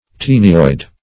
tenioid - definition of tenioid - synonyms, pronunciation, spelling from Free Dictionary Search Result for " tenioid" : The Collaborative International Dictionary of English v.0.48: Tenioid \Te"ni*oid\, a. See Taenoid .
tenioid.mp3